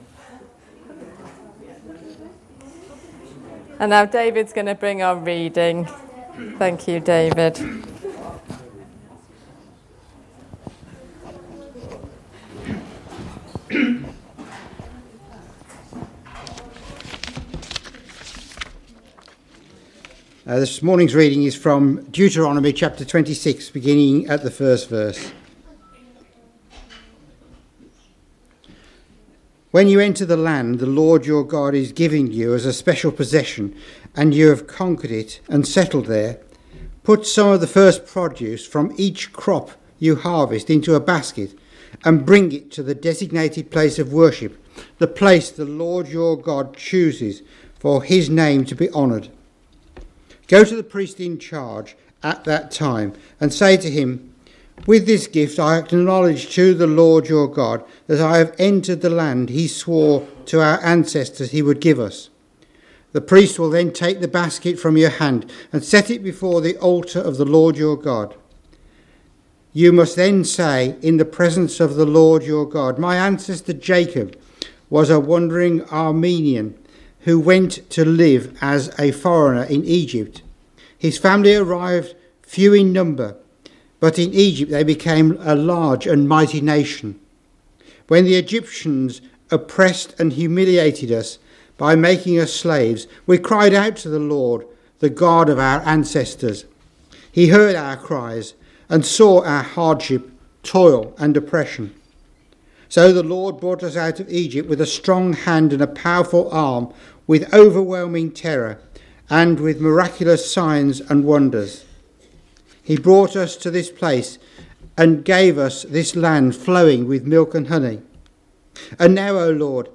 Sunday was our Harvest service